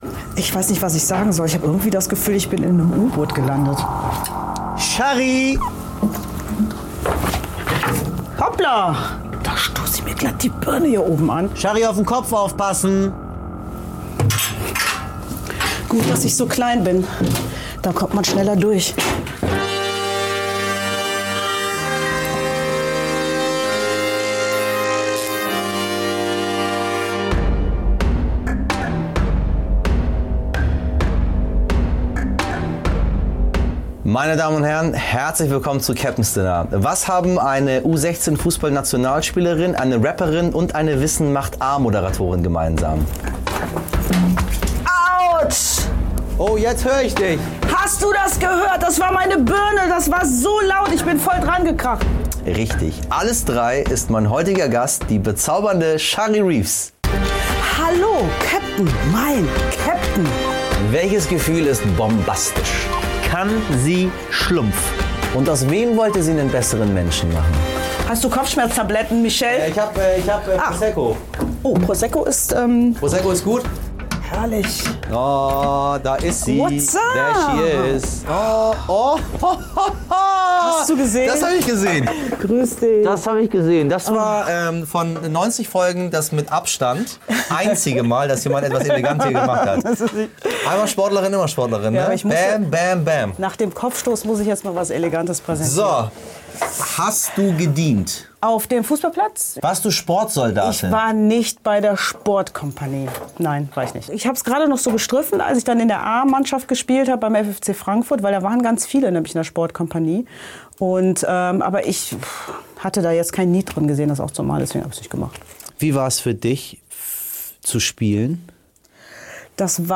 Bei Michel Abdollahi im U-Boot ist Moderatorin und Schauspielerin Shary Reeves zu Gast.